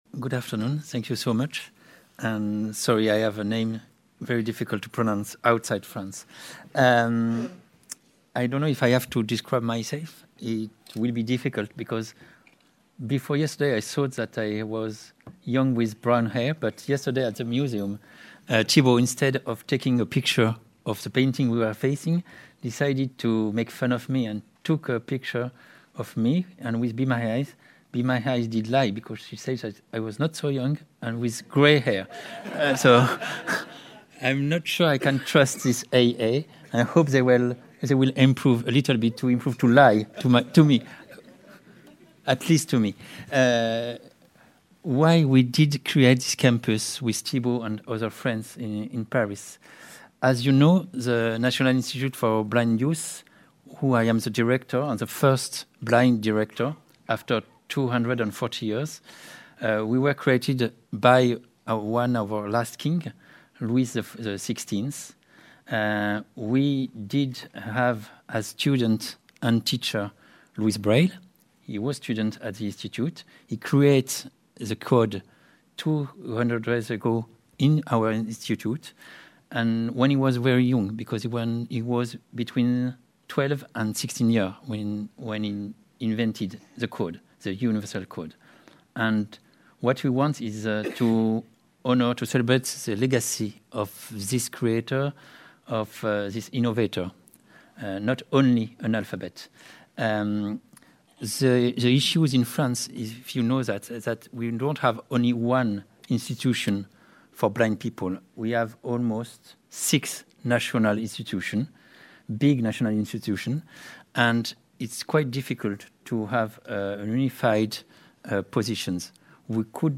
Innovation and Braille session from day one of RNIB Scotland's Inclusive Design for Sustainability Conference.